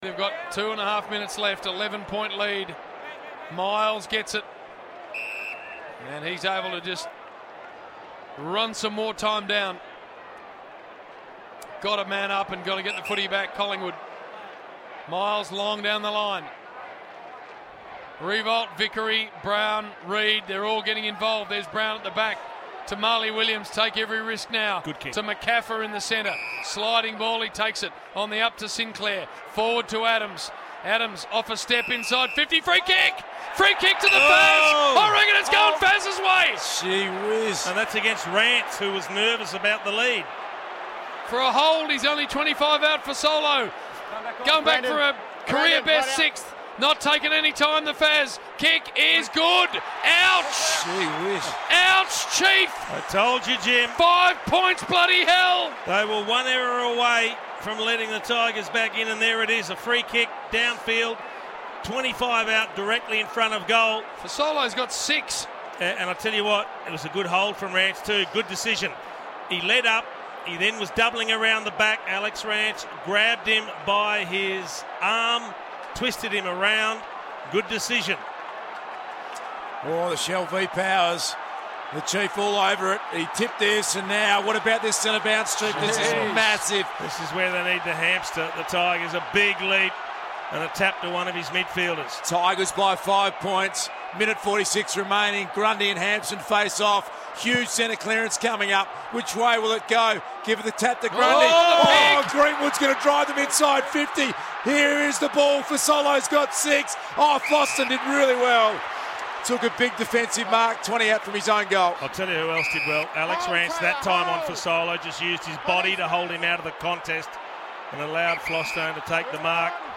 Listen to the Triple M radio team call the final five minutes of Collingwood's thrilling one-point win over Richmond at the MCG in round two, 2016.